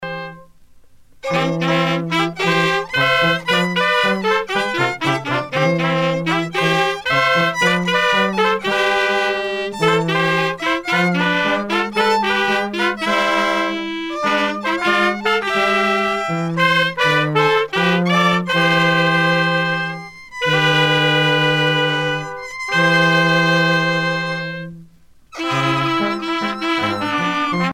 Usage d'après l'analyste gestuel : danse ;
groupe folklorique
Pièce musicale éditée